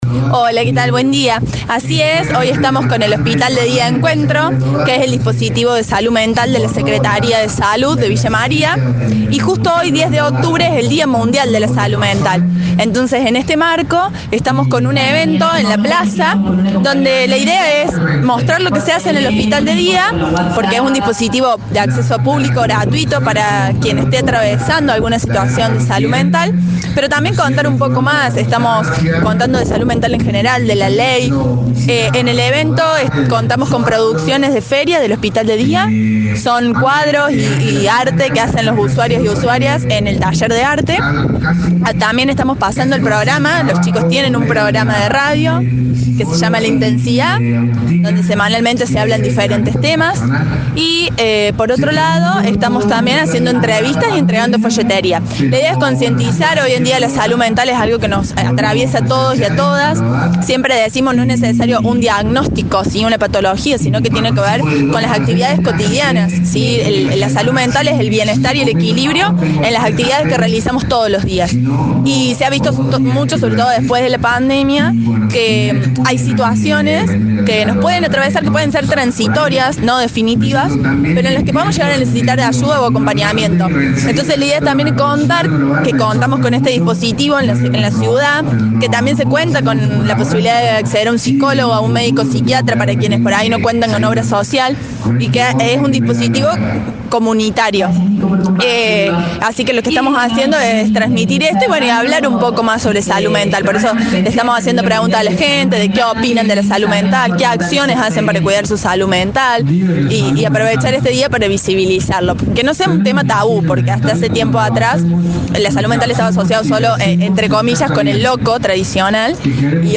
TERAPISTA OCUPACIONAL